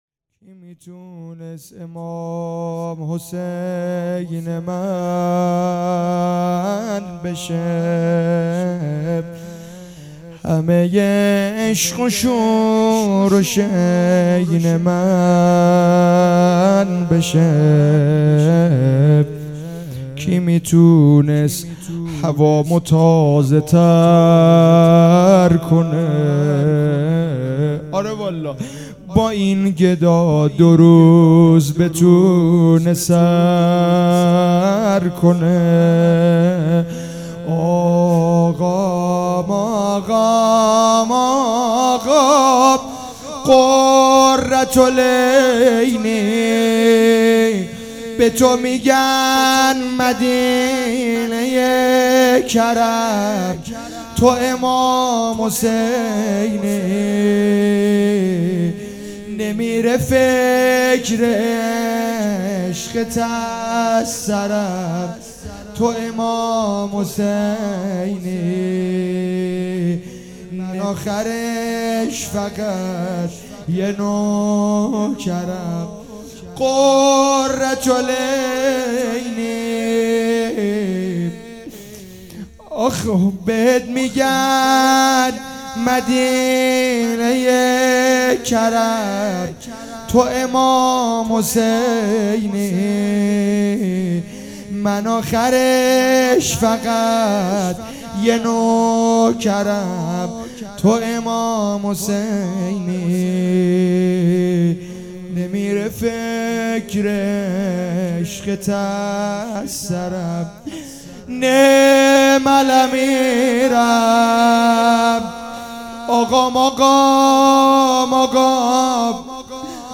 اقامه عزای شهادت امام هادی علیه السلام